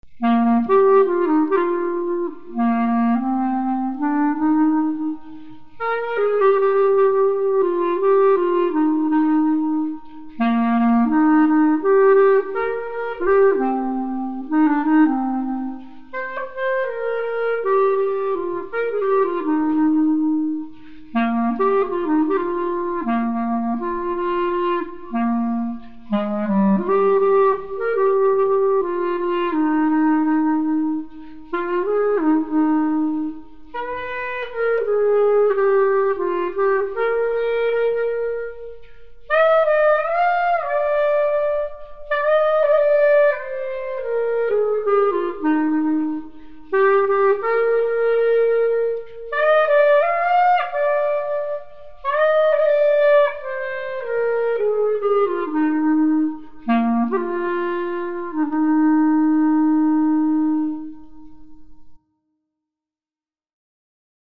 כלי נגינה - צליל יפה בקלרינט